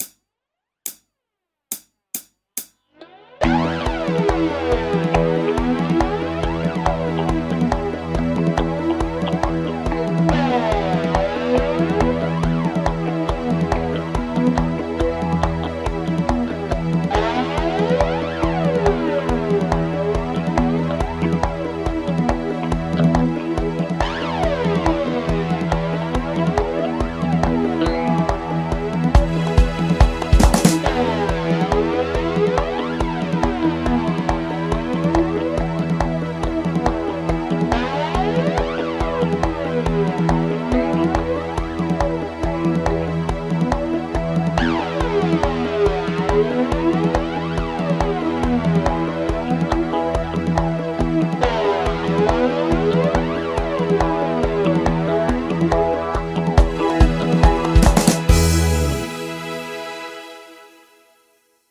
アラビアン・スケール ギタースケールハンドブック -島村楽器